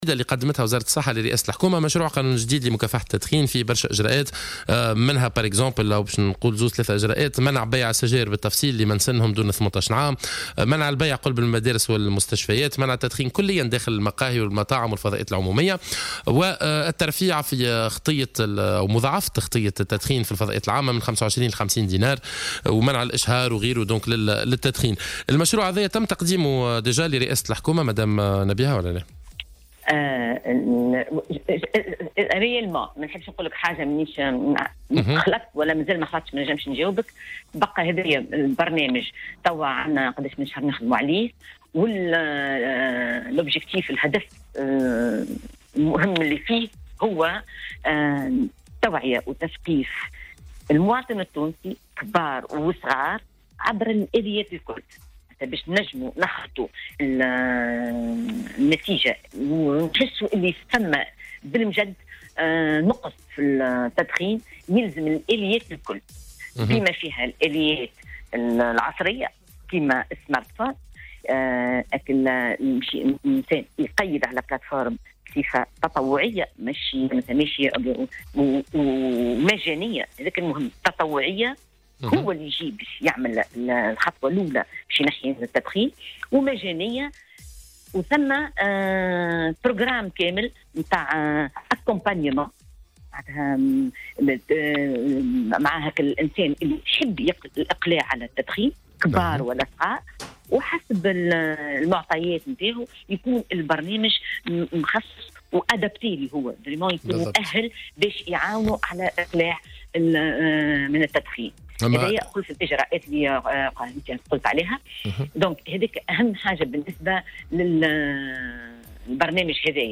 قالت المديرة العامة للصحّة نبيهة برصالي فلفول في تصريح للجوهرة "اف ام" اليوم الثلاثاء 2 جانفي 2018 إن مشروع القانون الجديد لمكافحة التدخين الذي اعدته وزارة التربية سيعرض على الحكومة للمصادقة عليه قريبا .